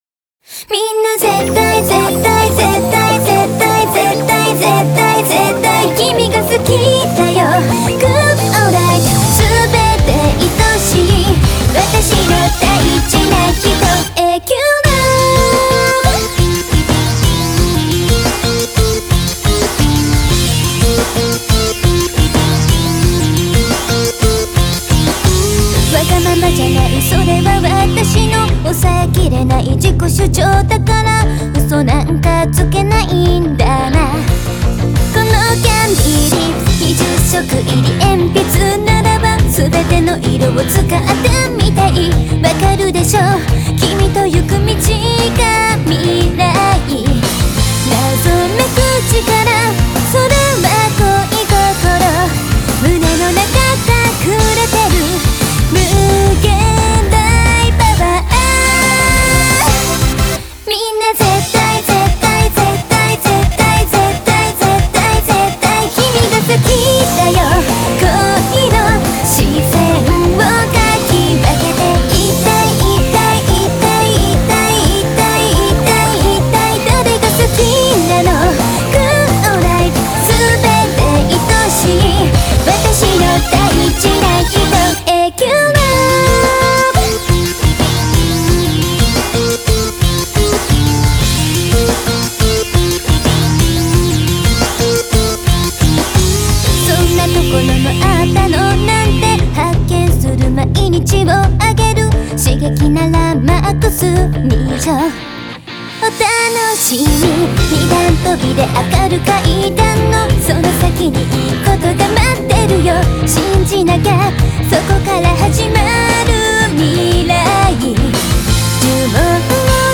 Country: Japan, Genre: J-Pop